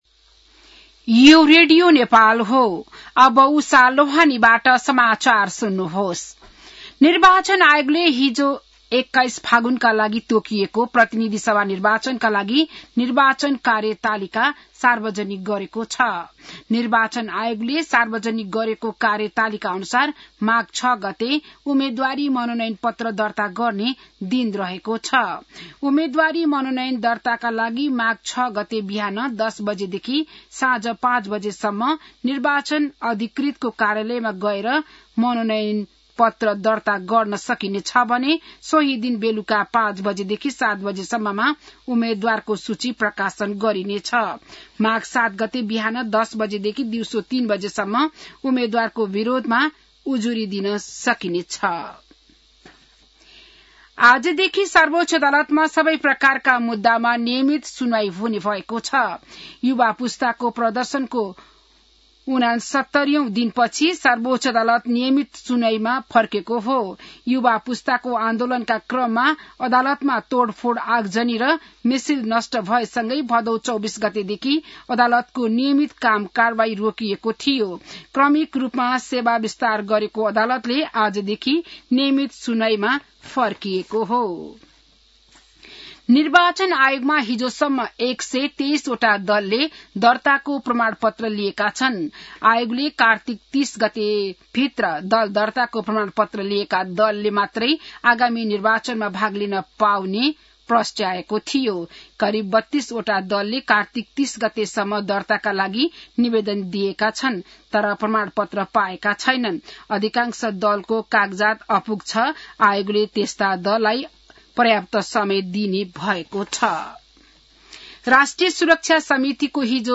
बिहान १० बजेको नेपाली समाचार : १ मंसिर , २०८२